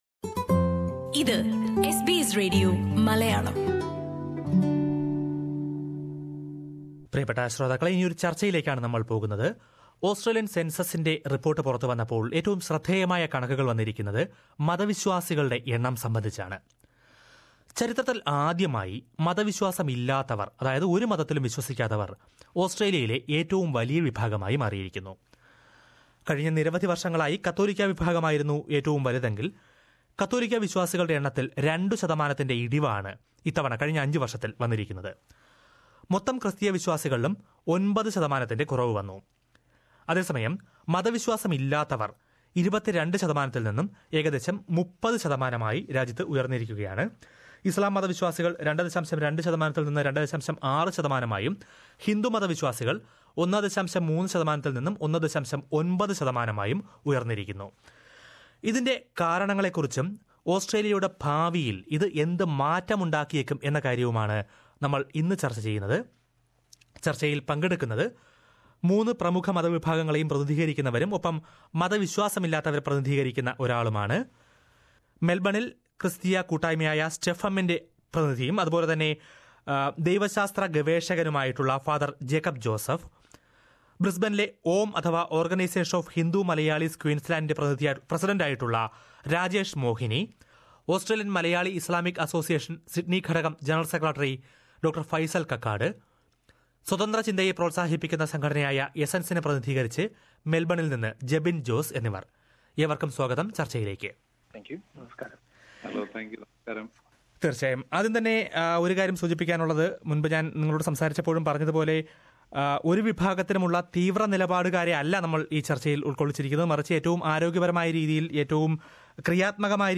ഈ വിഷയത്തിൽ എസ് ബി എസ് മലയാളം റേഡിയോ സംഘടിപ്പിച്ച ചർച്ച കേൾക്കാം.